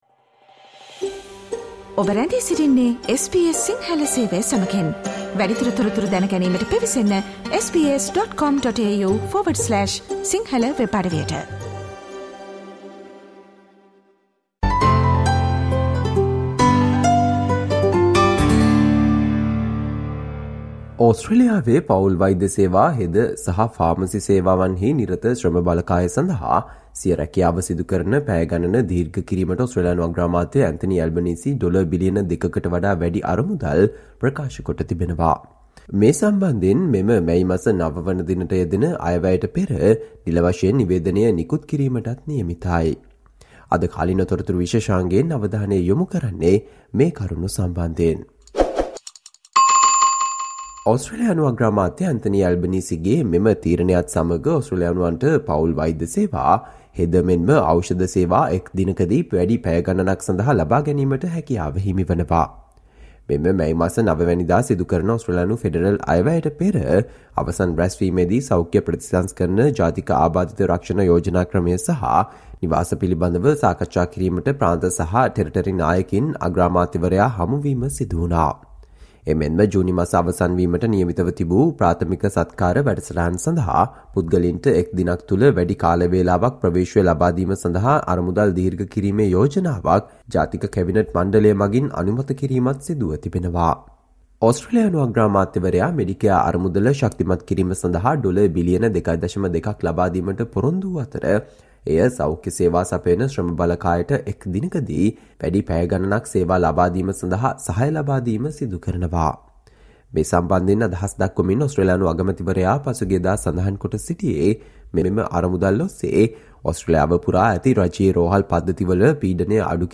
Today - 01 May, SBS Sinhala Radio current Affair Feature on Greater access to primary health care programs for Australians